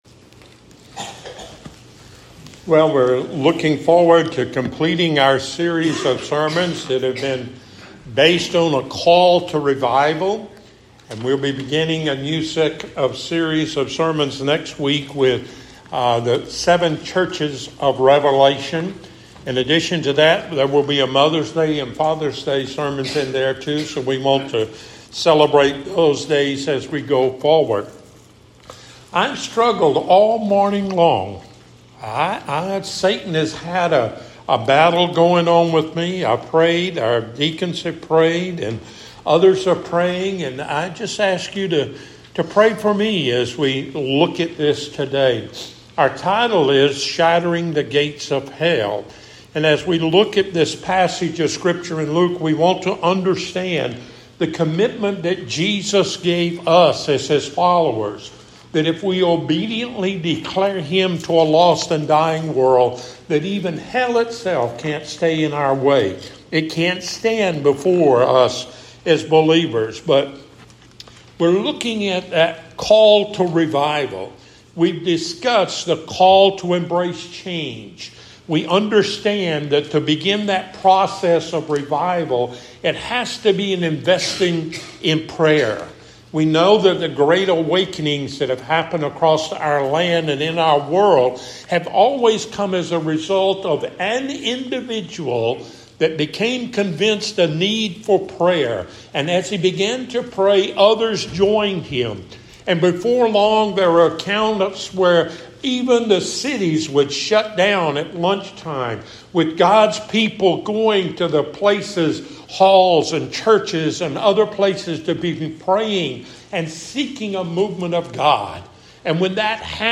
Matthew 16:13-19 Service Type: Sunday Morning Topics